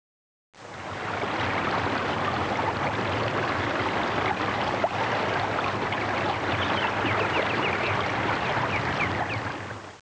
ALL YOU HEAR IS A MOUNTAIN STREAM
You'll be surrounded by the peaceful sounds of nature, a mountain stream with birds, crickets, frogs -- all designed to blend into the background while you go about your life.
The messages are recorded below the conscious hearing level.